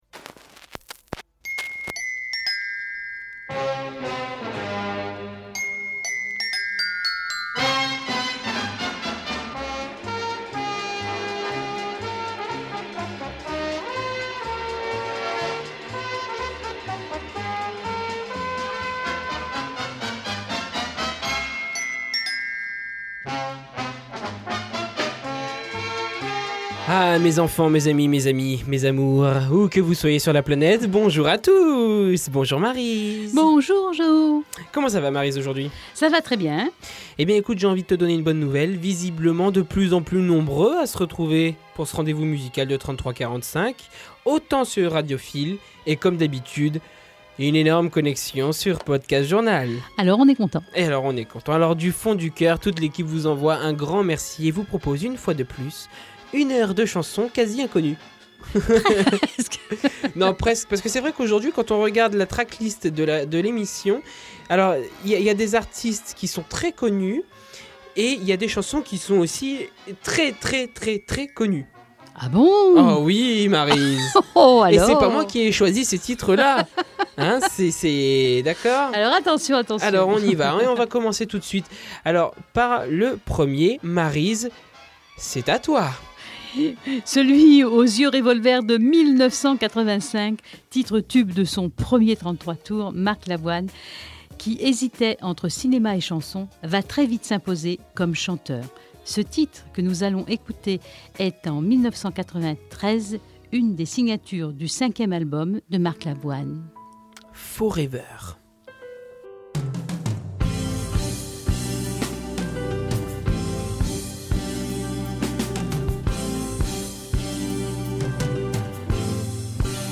Cliquez sur l'image pour accéder au site Radio Fil 3345_emission16.mp3 (50.1 Mo) Écoutez l'émission intégrale en podcast audio!
La différence, c'est de vous faire écouter les faces cachées passées aux oubliettes, des titres étouffés par les tubes de ces années vinyles.